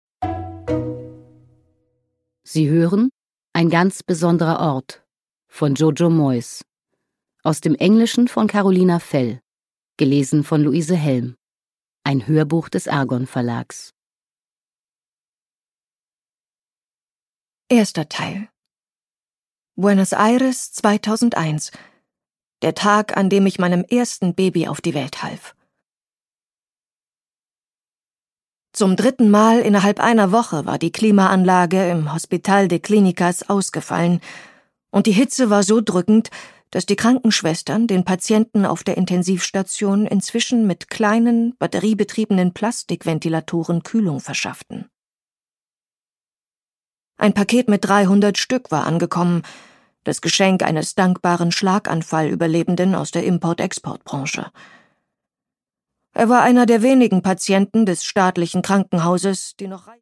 Produkttyp: Hörbuch-Download
Gelesen von: Luise Helm